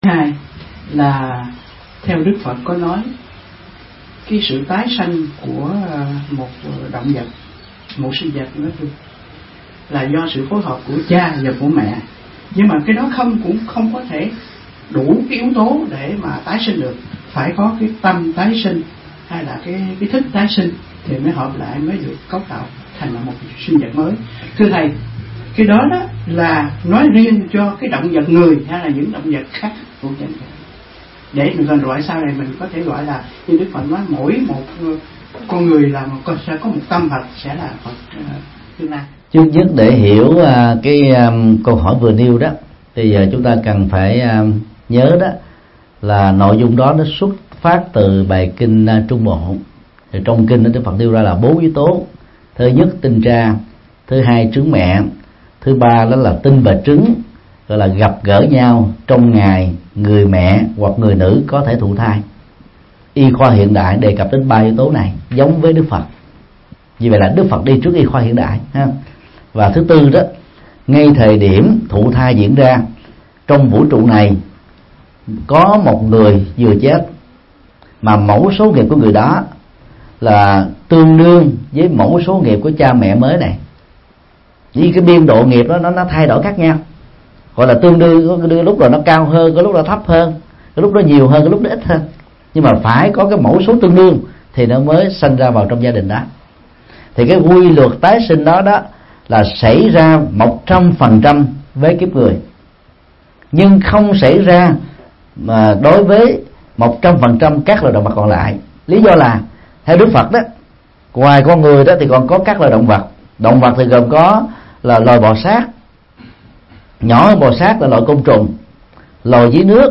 Vấn đáp: Điều kiện để tái sinh